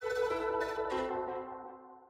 SFX_Quest_Fail_Placeholder_01.wav